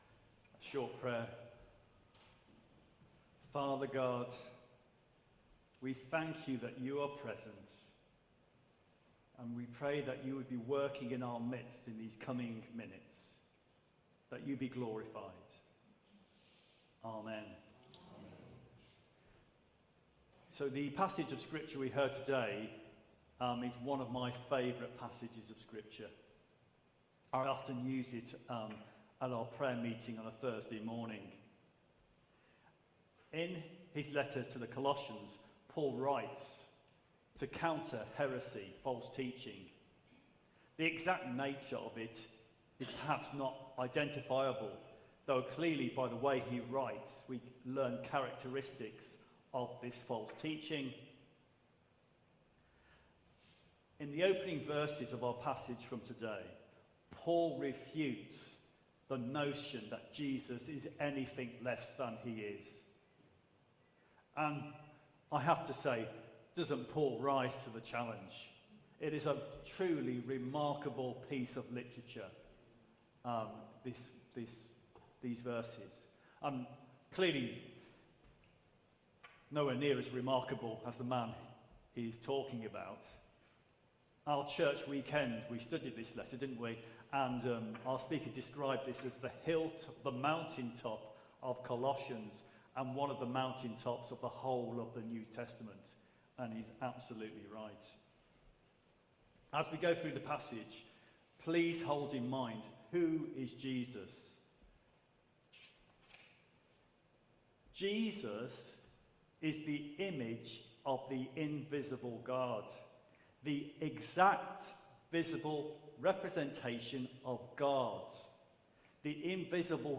Media for Holy Communion on Sun 20th Jul 2025 09:00 Speaker